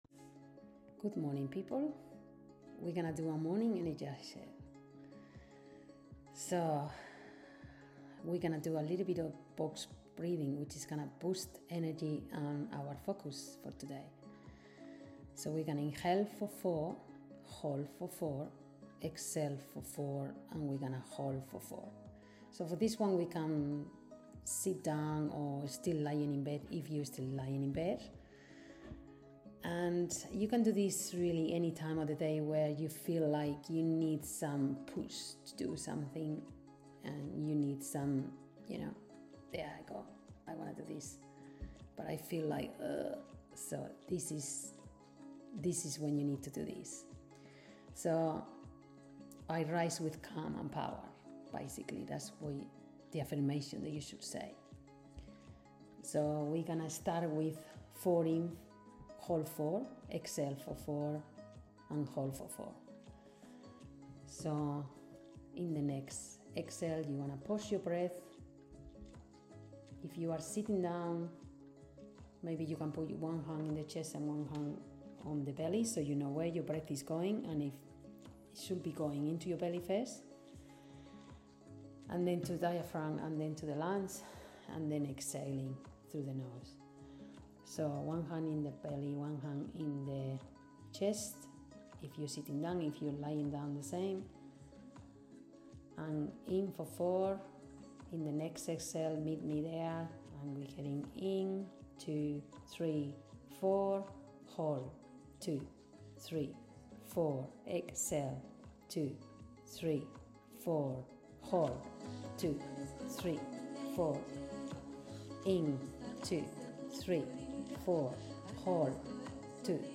PRACTICE: 4-4-4-4 Box Breathing
Morning Energizer Guide Breathwork MP3 • 5746KB